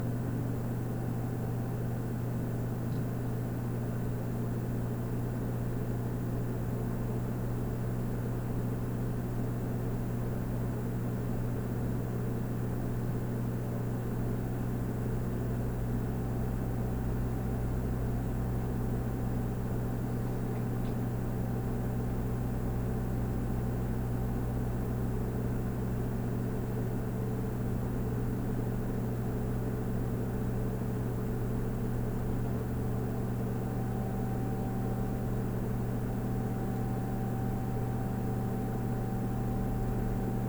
Computers, Hard Drive, Desktop, Fans, Power Up, Down, Run, Buttons SND51662.wav